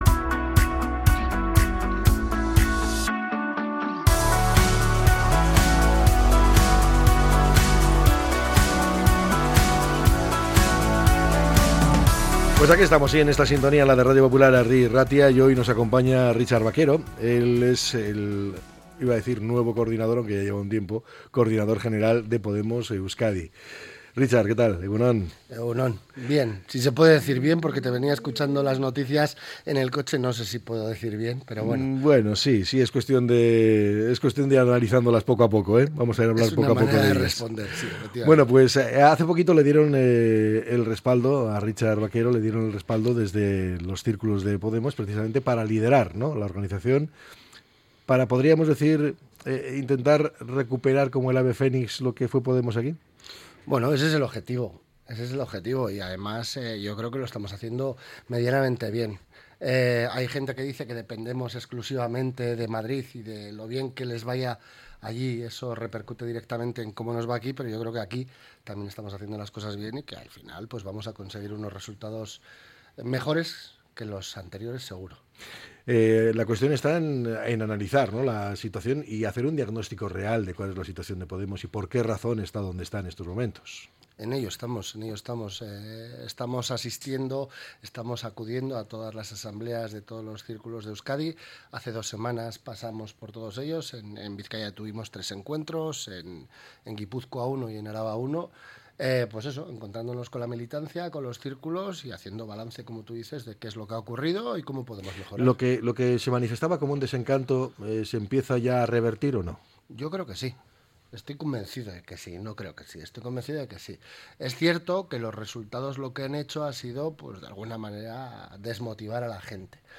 Entrevista con el coordinador general de Podemos Euskadi